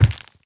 troll_archer_walk_left.wav